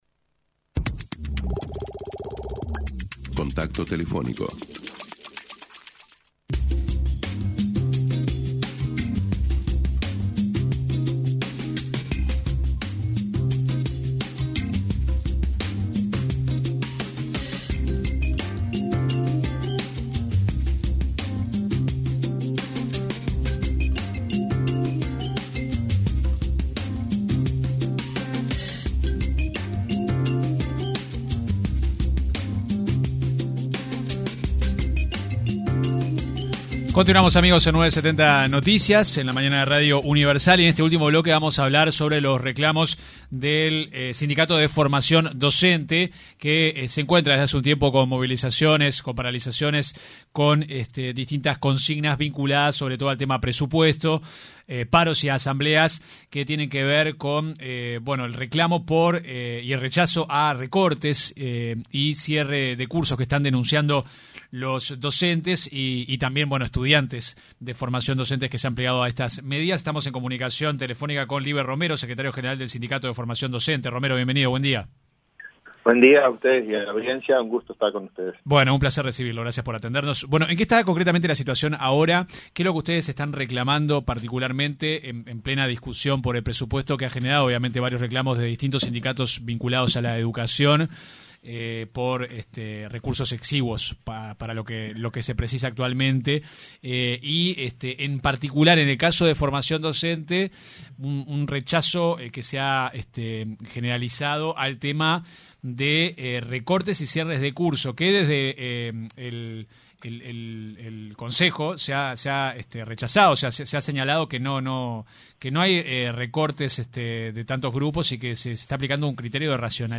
en diálogo con 970 Noticias